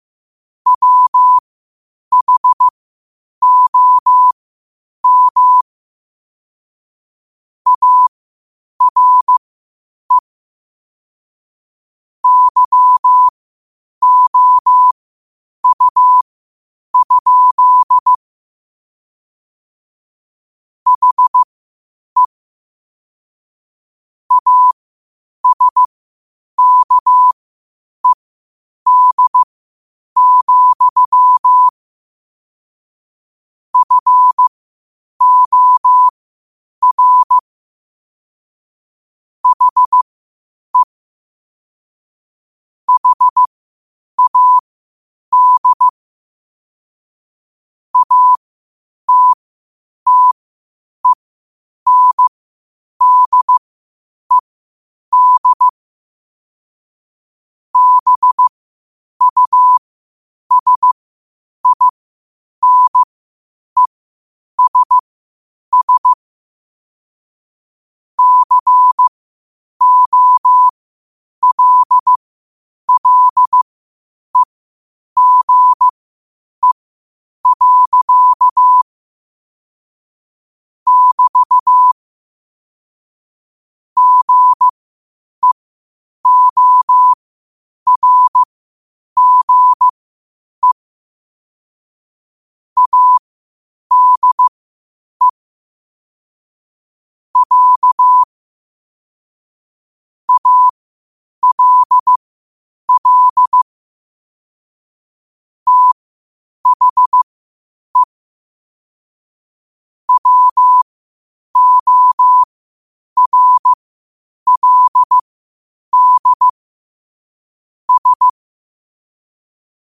Quotes for Sat, 16 Aug 2025 in Morse Code at 8 words per minute.
Play Rate Listened List Bookmark Get this podcast via API From The Podcast Podcasts of famous quotes in morse code.